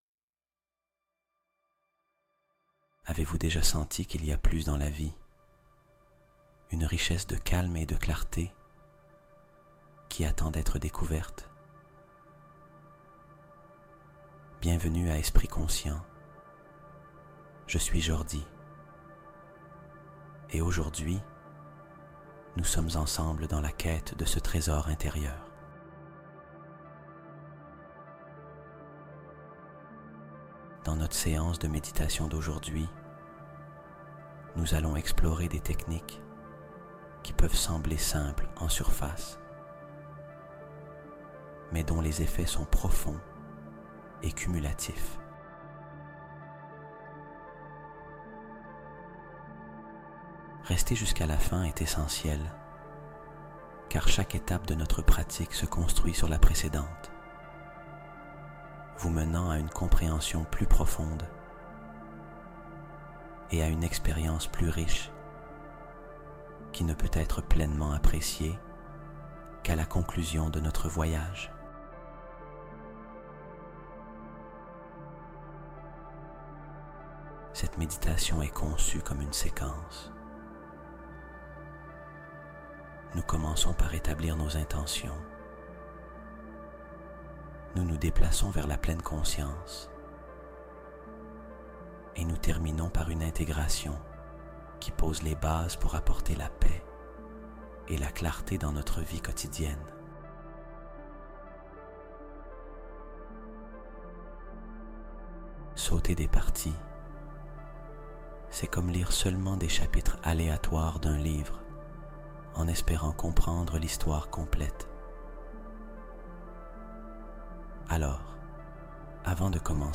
Clé d'or du sommeil | Méditation guidée pour repos profond et manifestation nocturne